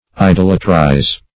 idolatrize - definition of idolatrize - synonyms, pronunciation, spelling from Free Dictionary
Search Result for " idolatrize" : The Collaborative International Dictionary of English v.0.48: Idolatrize \I*dol"a*trize\, v. i. [imp.